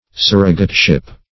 Surrogateship \Sur"ro*gate*ship\, n. The office of a surrogate.